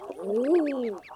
Ærfuglen
Ærfuglen Dette er lyden av Ærfuglen Last ned lyden
aerefugl-oh.mp3